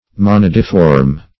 Search Result for " monadiform" : The Collaborative International Dictionary of English v.0.48: Monadiform \Mo*nad"i*form\, a. [Monad + -form.]